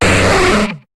Cri d'Hyporoi dans Pokémon HOME.